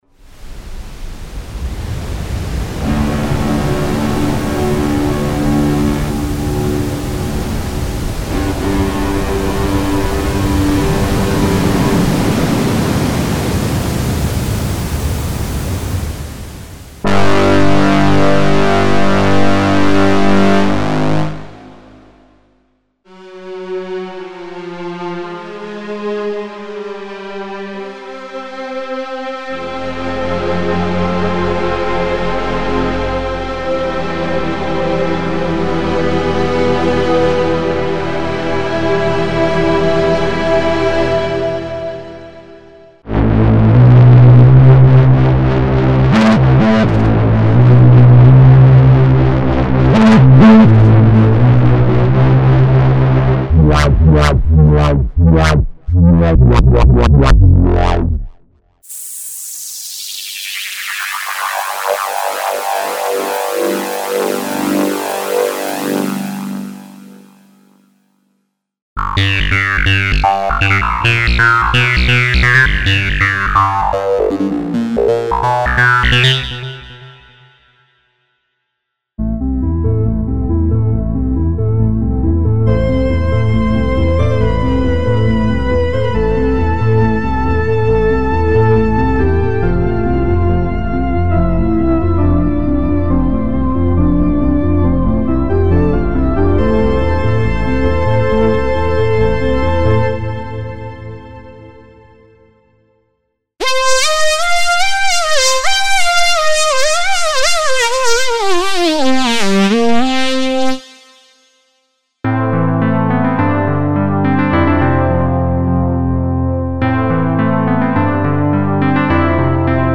Here is a demonstration of some of the 128 presets that are included with Glass Viper. It shows the synthesizer's broad range of abilities and natural evolving sounds. All segments are simple and hand played, and only Glass Viper was used. No other effects, enhancement or compression is used in this demos.